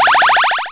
startgame.wav